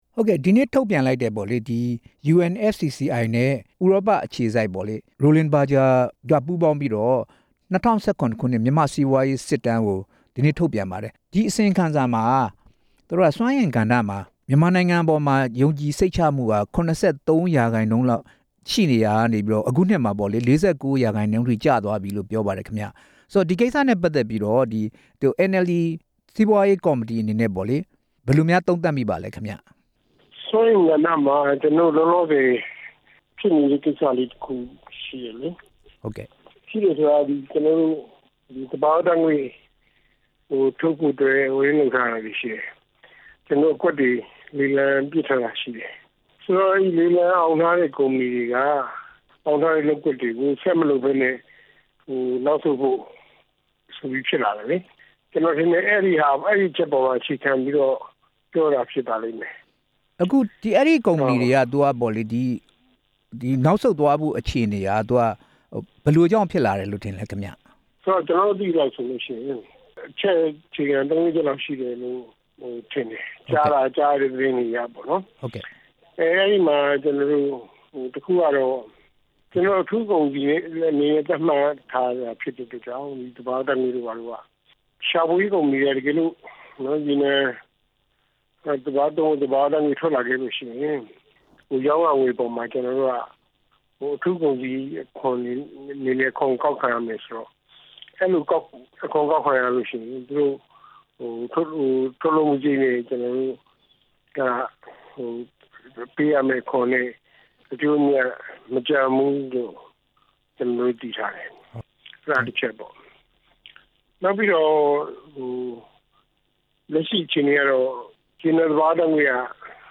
၂၀၁၇ မြန်မာ့စီးပွားရေး စစ်တမ်းအကြောင်း မေးမြန်းချက်